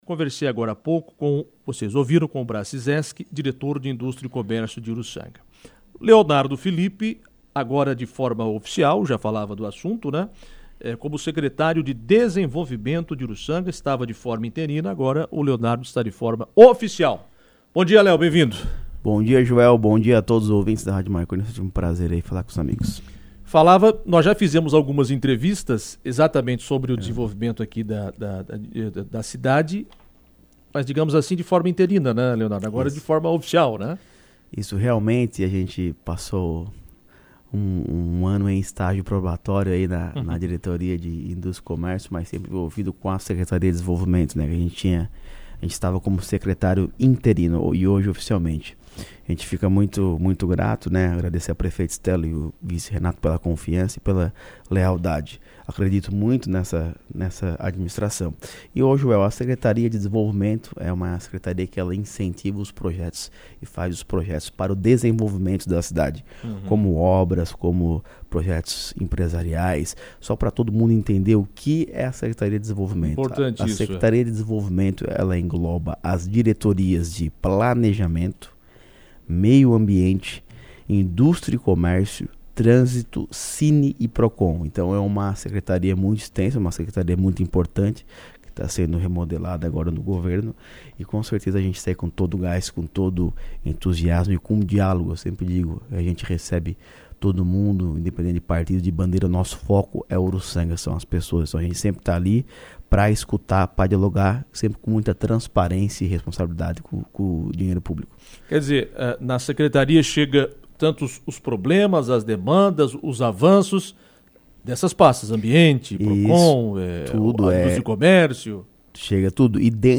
Em entrevista, Leonardo citou algumas obras que estão sendo realizadas em Urussanga, como o processo de reforma de escolas no Rio Caeté e Bom Jesus, campo esportivo e unidade de saúde no bairro De Villa, manutenção do posto em Santana e outros projetos.